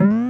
Sound effects[edit]
Boing - When King Zing is hit without taking damage.
Boing_(DKC2).oga